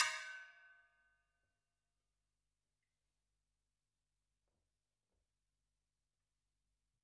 描述：大金属罐，用鼓棒的各种敲击声用一个EV RE20和两个压缩的omni麦克风录制的立体声大量的房间声音
Tag: 工业 金属 金属 大锡